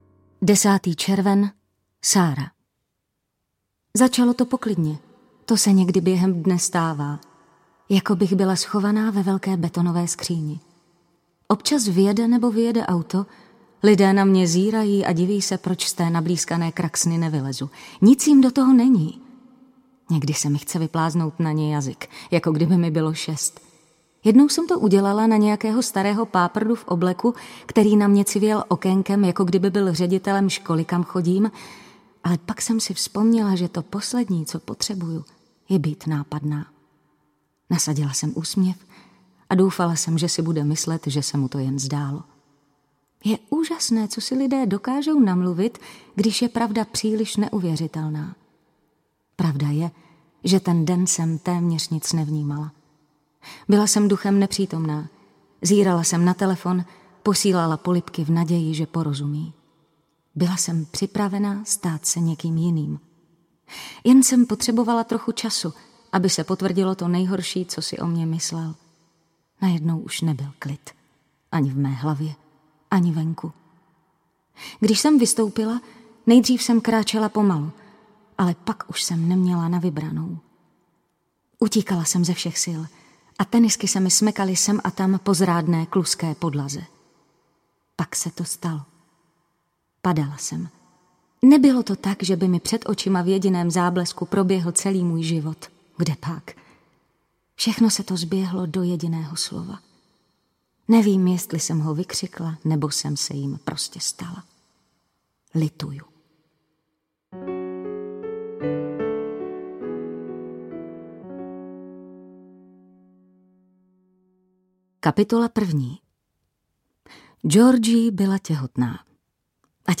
Až moc blízko audiokniha
Ukázka z knihy
az-moc-blizko-audiokniha